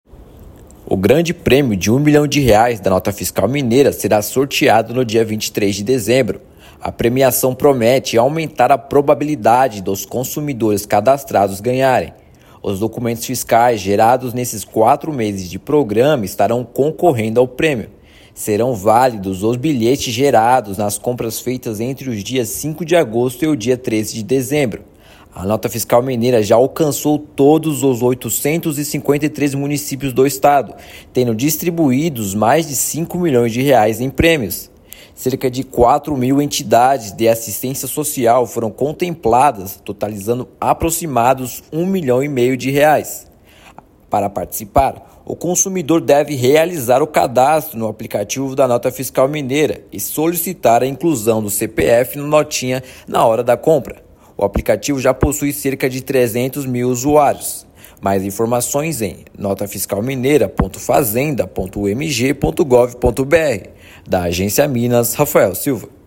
Poderão participar bilhetes gerados nas compras realizadas desde o lançamento do programa, em agosto, até o dia 13/12. Ouça matéria de rádio.